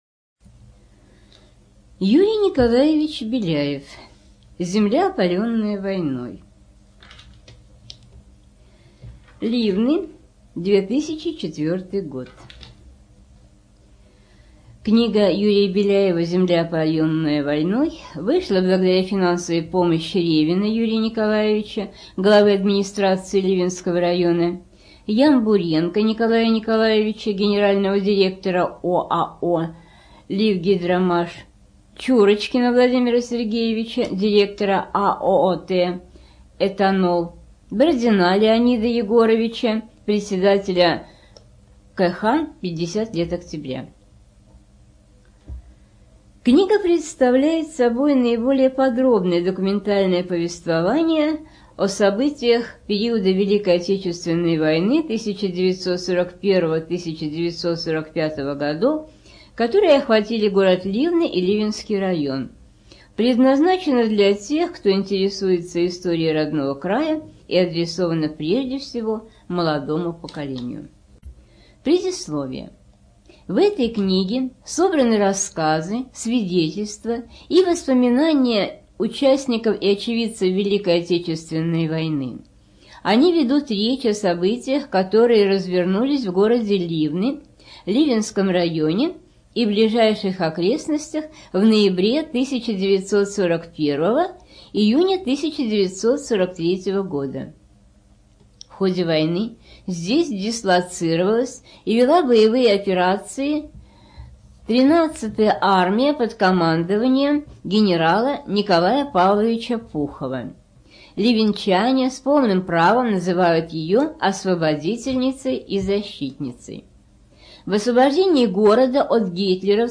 Студия звукозаписиОрловская областная библиотека для слепых